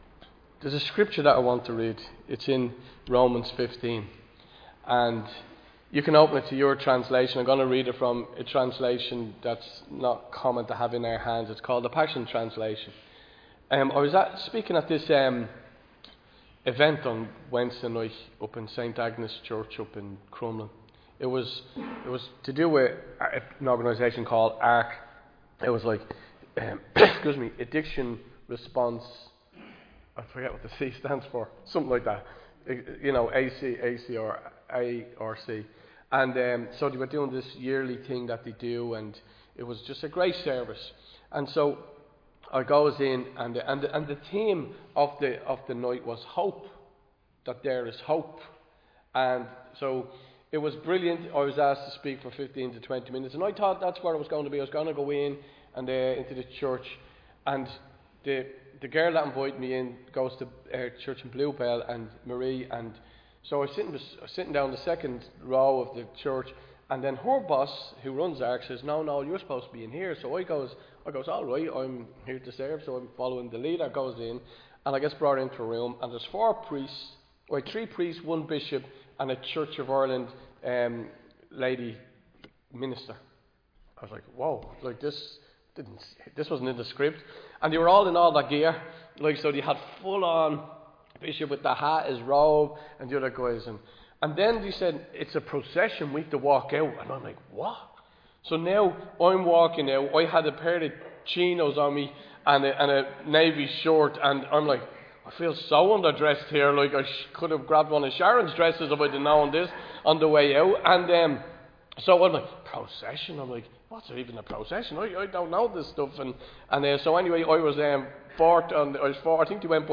Recorded live in Liberty Church on 18 May 2025